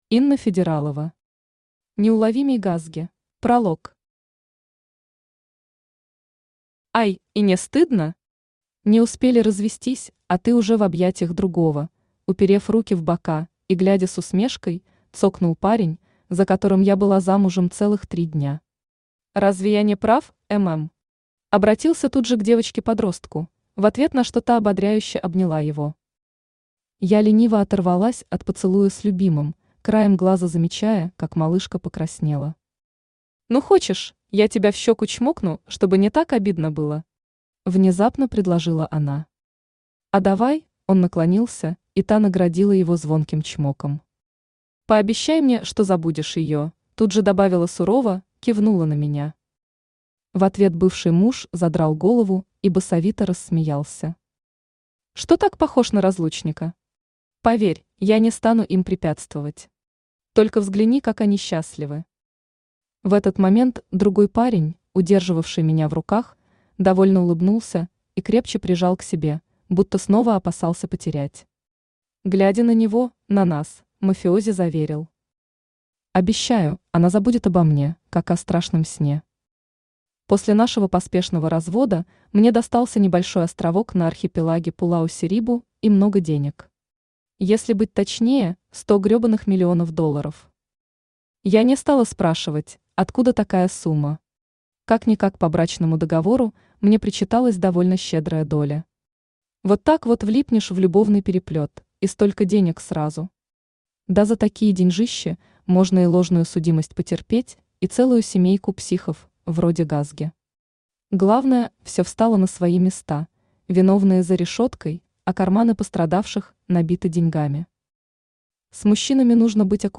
Аудиокнига Неуловимый Гасги | Библиотека аудиокниг
Aудиокнига Неуловимый Гасги Автор Инна Федералова Читает аудиокнигу Авточтец ЛитРес.